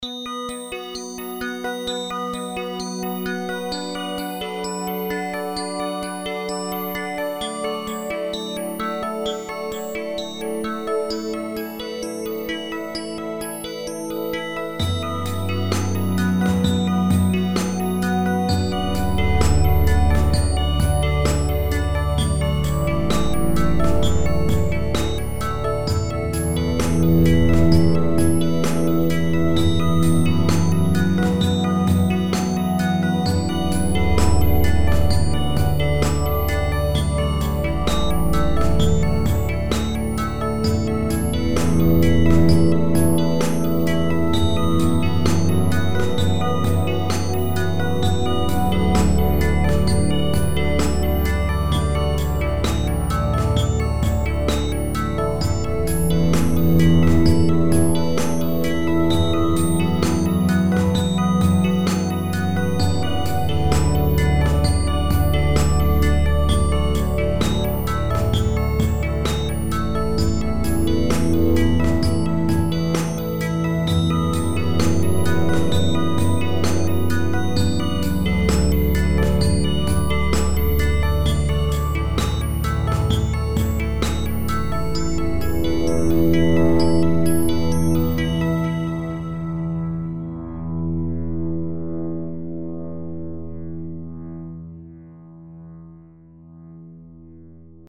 Ambient